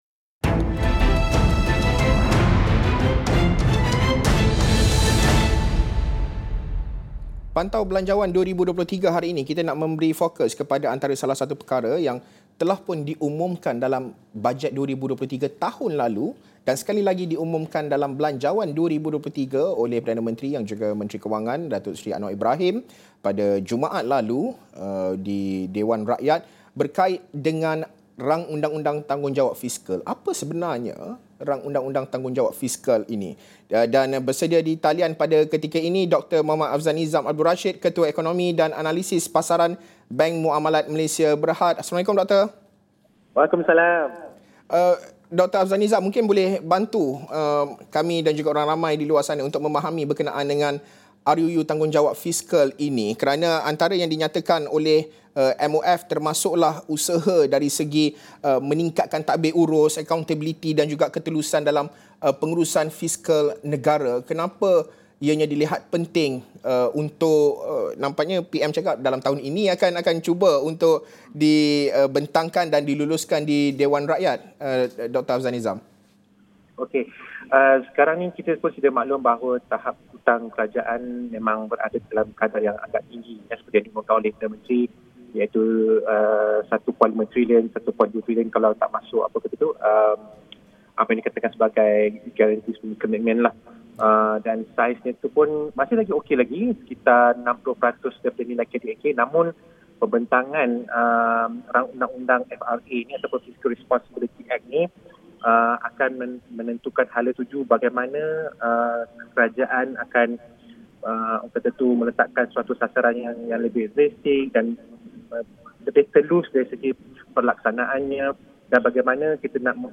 memperincikan RUU ini bersama beberapa panel jam 11 pagi ini.